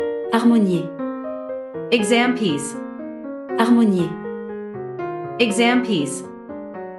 • 人声数拍